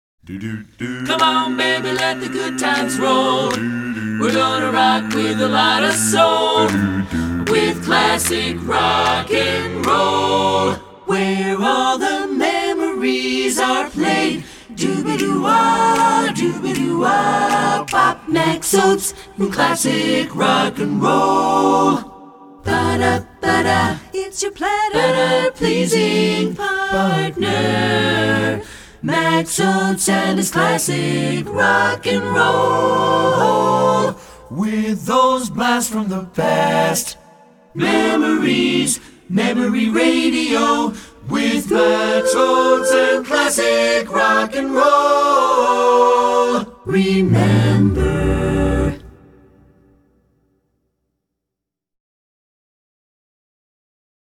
oldies a capellas